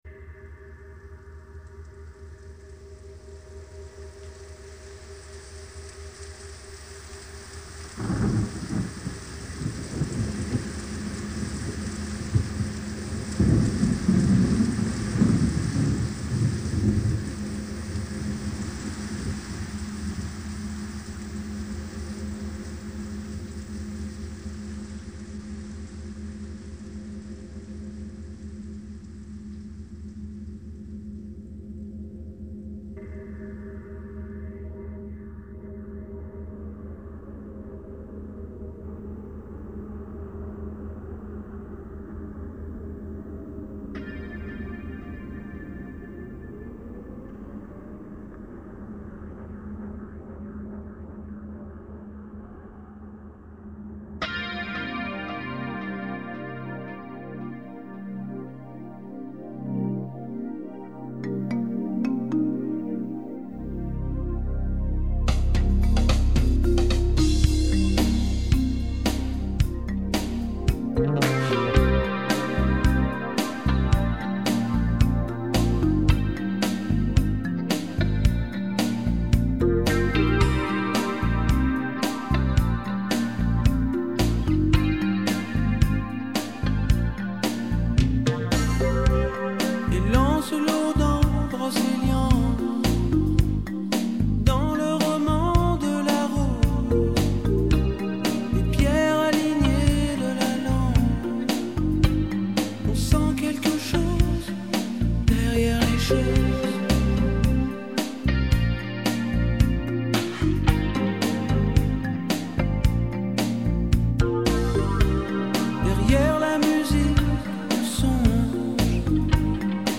tonalité de MIb majeur